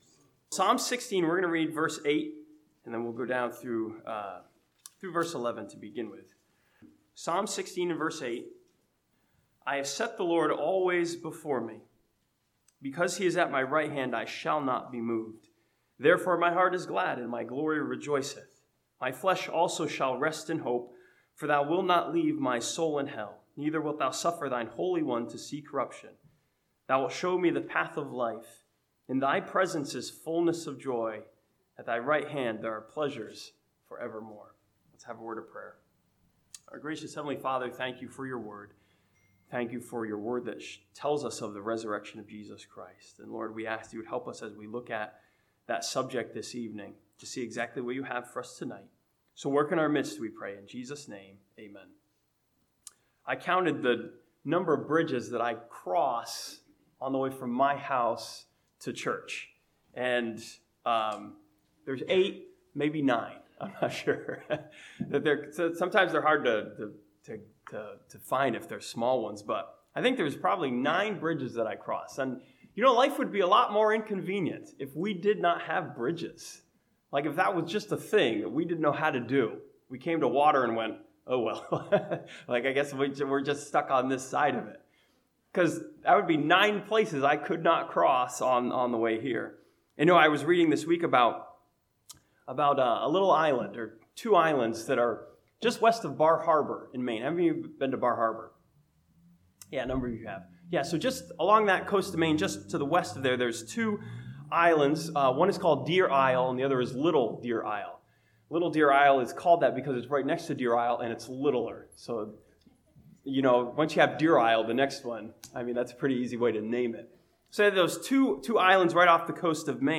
This Easter sermon from Psalm 16 sees the Resurrection as the bridge that Jesus built for us over death and hell.